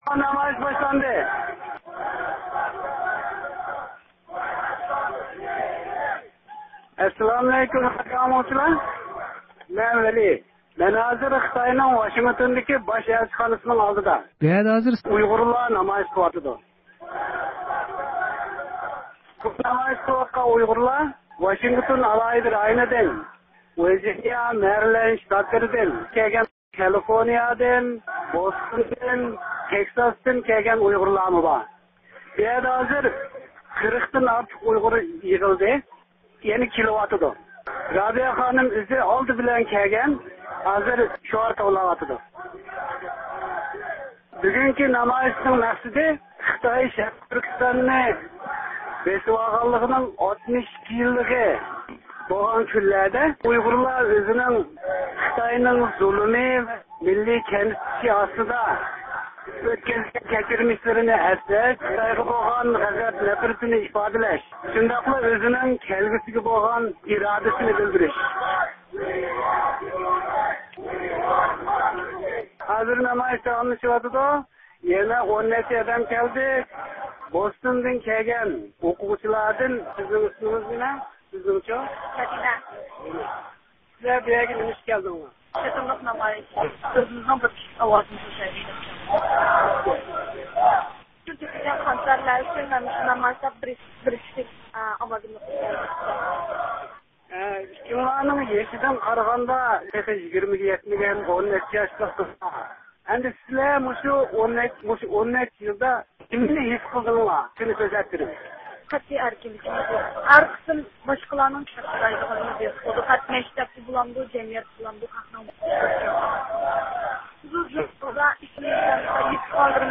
10-ئاينىڭ 3- كۈنى ئامېرىكا پايتەختى ۋاشىنگتوندىكى خىتاي باش ئەلچىخانىسى ئالدىدا خىتاي كوممۇنىستلىرىنىڭ ئۇيغۇر ئېلىنى ئىشغال قىلغانلىقىنىڭ 62 يىللىقى مۇناسىۋىتى بىلەن ئۇيغۇرلارنىڭ نارازىلىق نامايىشى ئۆتكۈزۈلدى.
نامايىشتا ئۇيغۇر مىللىي ھەرىكىتىنىڭ رەھبىرى رابىيە قادىر خانىم سۆز قىلىپ، خىتاي كوممۇنىستلىرىنىڭ ئۇيغۇر ئېلىنى بېسىۋالغانلىقىغا 62 يىل تولغانلىقى، خىتاينىڭ بۇ جەرياندا ئۇيغۇرلار ئۈستىدىن ئېغىر بېسىم ۋە دەپسەندە قىلىش سىياسىتى يۈرگۈزۈپ كەلگەنلىكىنى ئەيىبلىدى.